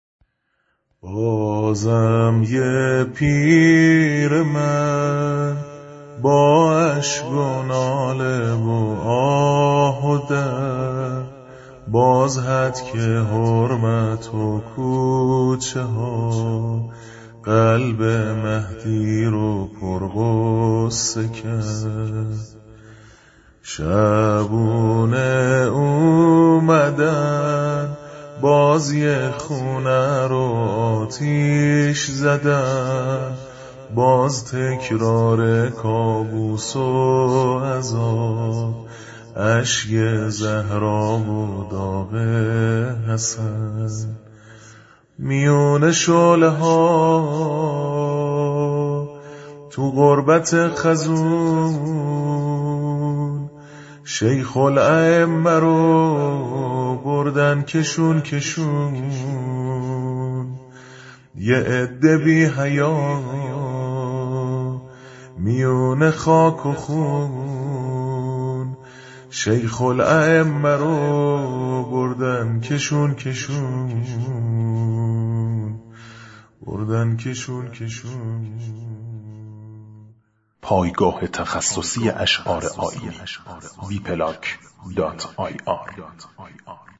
شور ، زمینه ، زمزمه